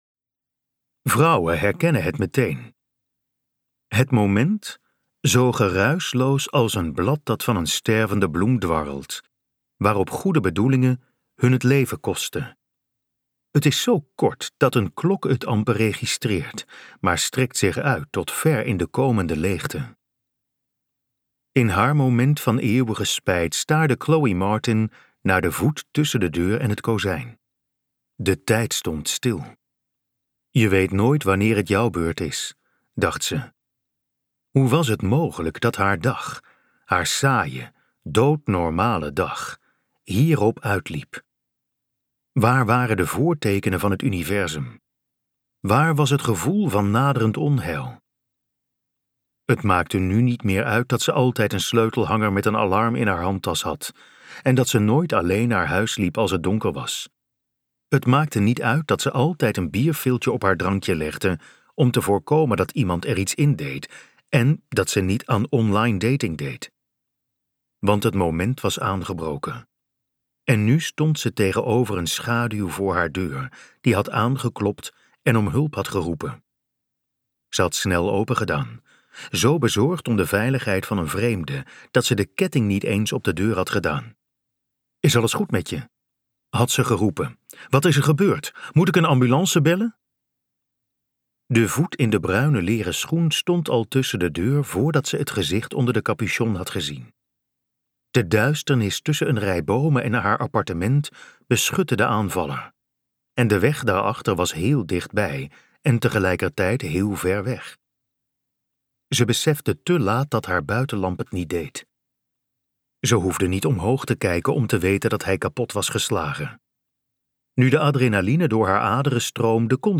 Ambo|Anthos uitgevers - Profiel m luisterboek